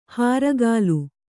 ♪ hāragālu